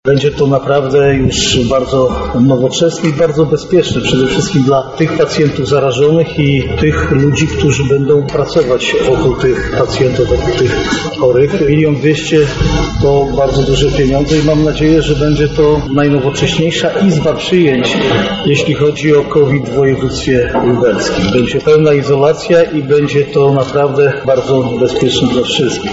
-mówi Marszałek Województwa Jarosław Stawiarski.